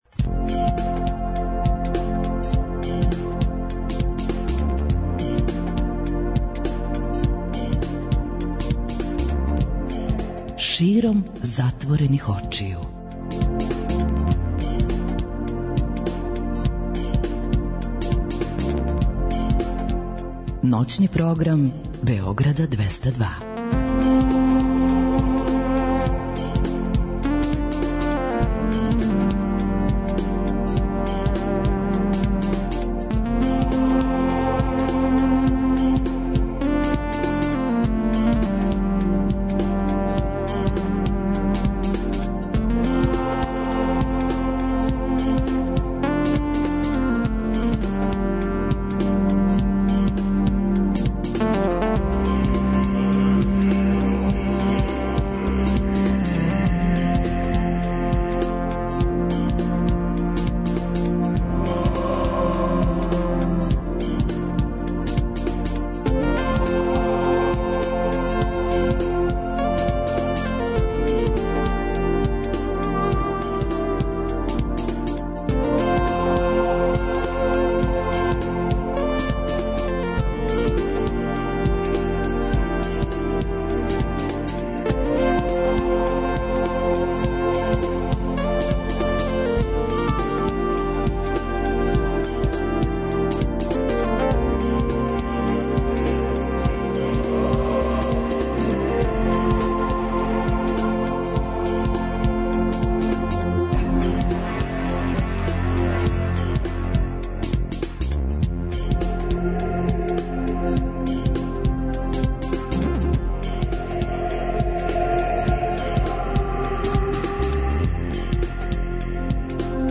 преузми : 42.44 MB Широм затворених очију Autor: Београд 202 Ноћни програм Београда 202 [ детаљније ] Све епизоде серијала Београд 202 Блузологија Свака песма носи своју причу Летње кулирање Осамдесете заувек!